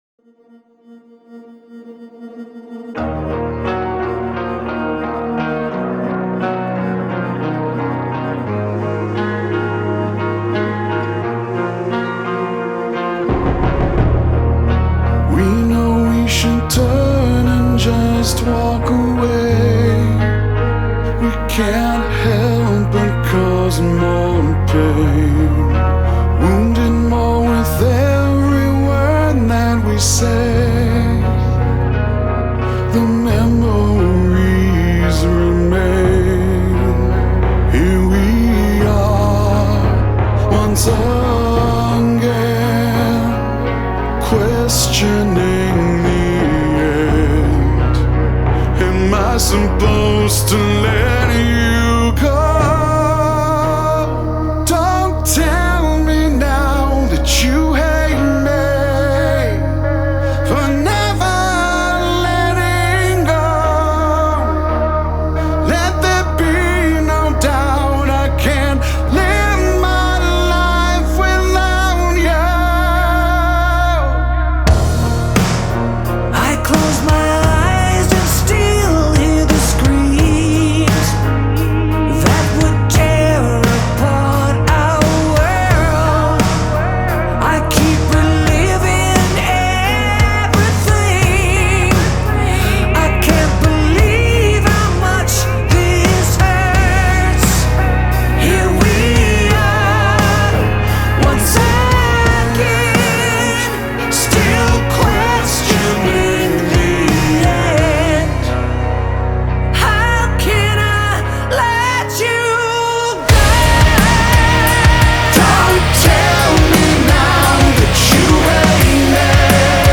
• Жанр: Rock